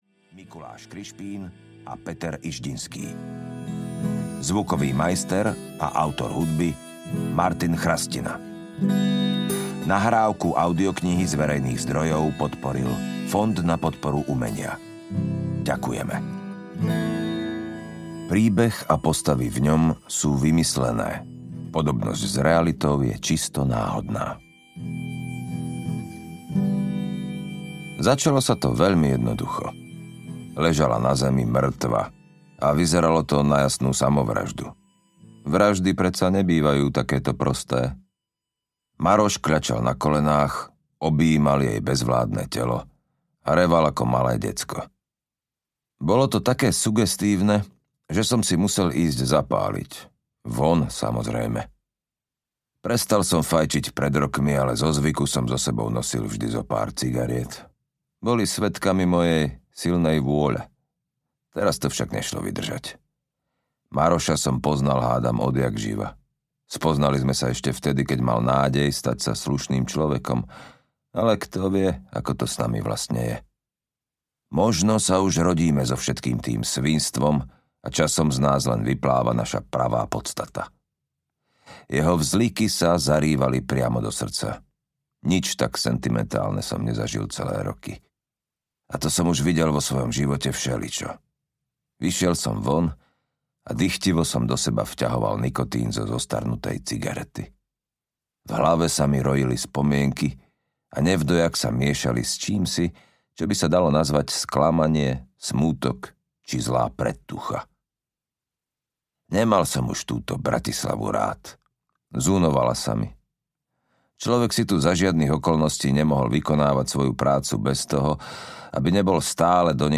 Šelmy a hyeny audiokniha
Ukázka z knihy